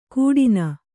♪ kūḍina